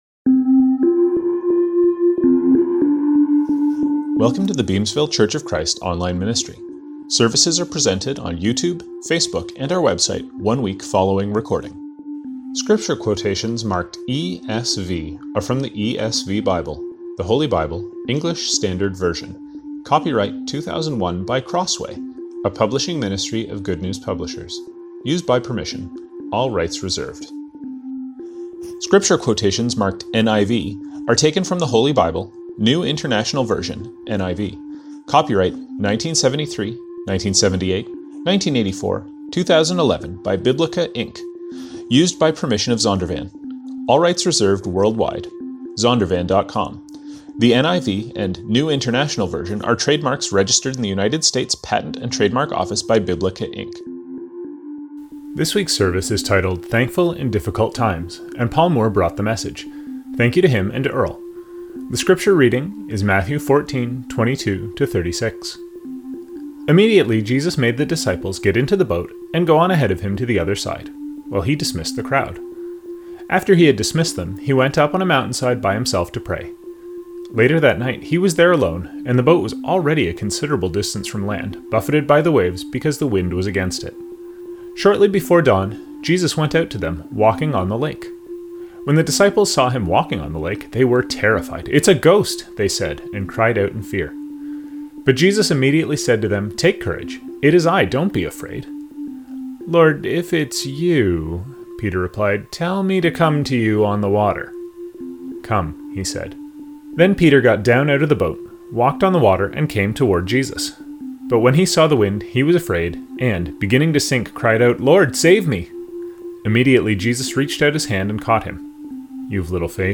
Scriptures from this service:Introduction: 1 Thessalonians 5:16-19Sermon: Matthew 14:22; 14:15-21; Mark 6:40; 6:39; John 6:15; Matthew 14:22-36; 2 Corinthians 1:3-4; 2 Corinthians 4:6-9; 4:13-15; 4:16; 1:4; 2 Corinthians 5:13-17; Mark 3:21.Communion: Hebrews 10:24; Colossians 3:15-17Closing: 1 Thessalonians 3:12-13; 5:23-24; 2 Thessalonians 2:16-17.